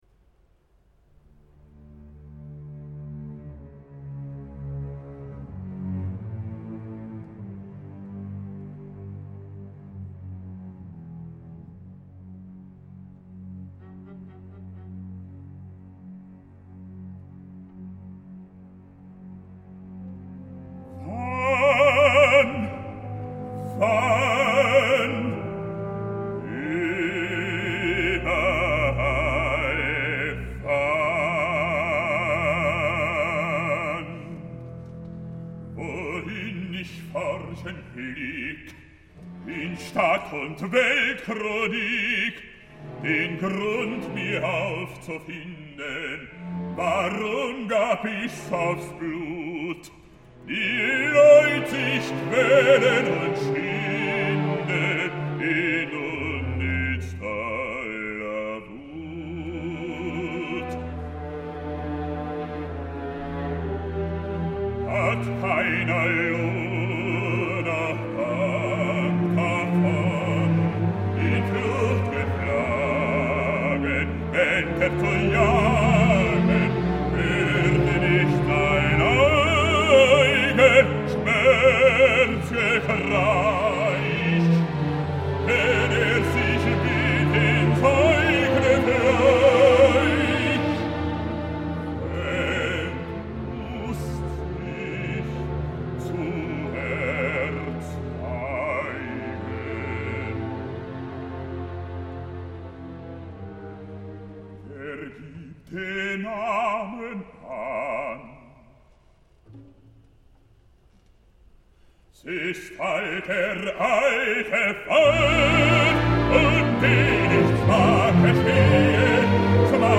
el baix baríton alemany